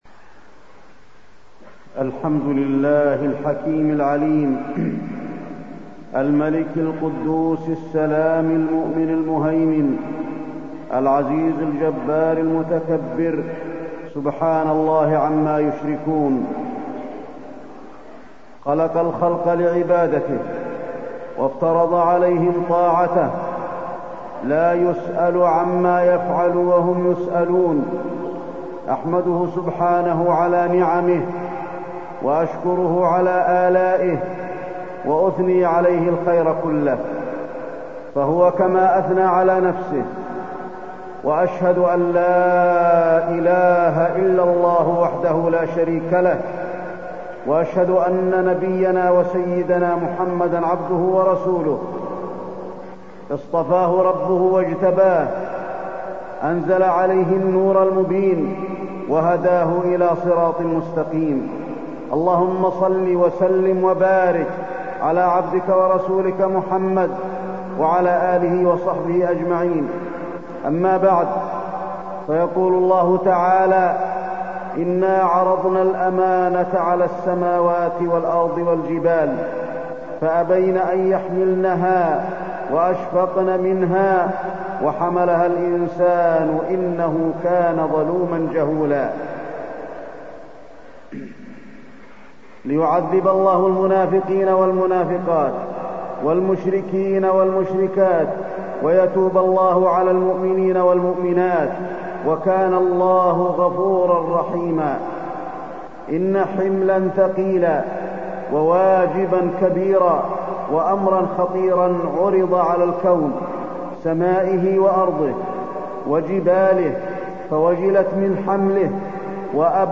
تاريخ النشر ١٤ ذو القعدة ١٤٢٣ هـ المكان: المسجد النبوي الشيخ: فضيلة الشيخ د. علي بن عبدالرحمن الحذيفي فضيلة الشيخ د. علي بن عبدالرحمن الحذيفي الأمانة